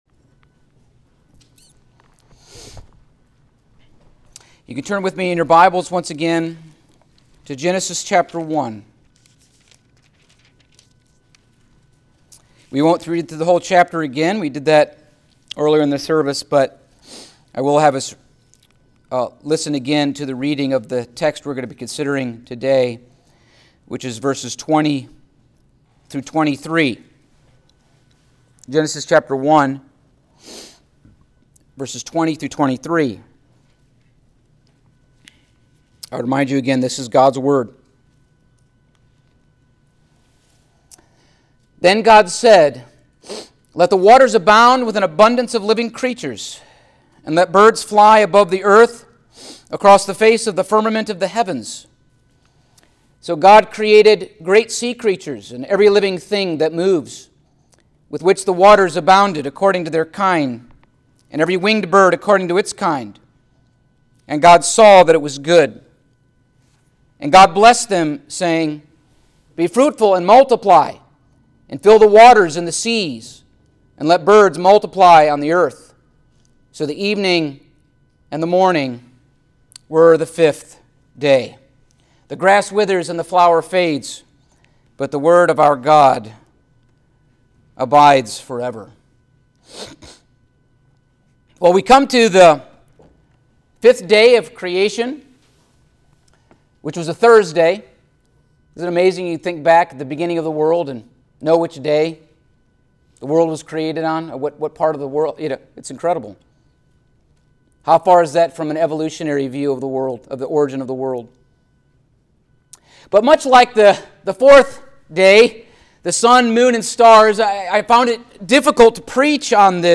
Passage: Genesis 1:20-23 Service Type: Sunday Morning